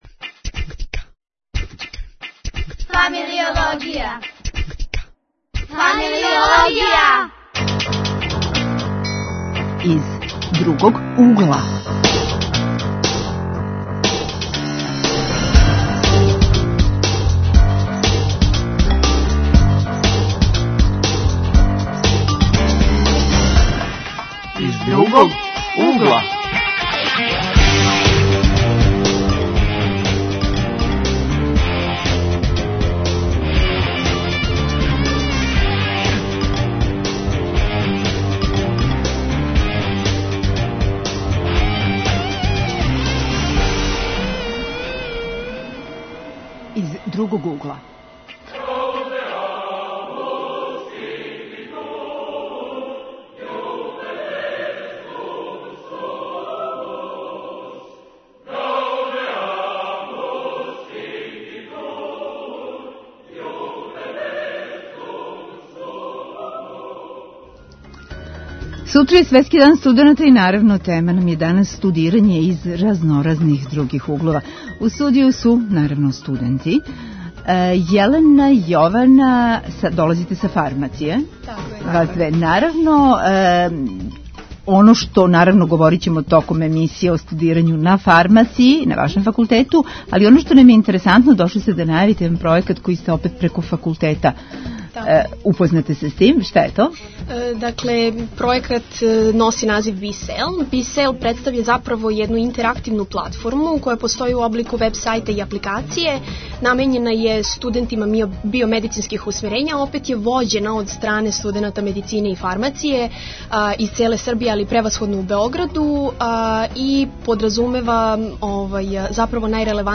Тема: студирање из разноразних других углова, у сусрет Дану студената. Гости: студенти.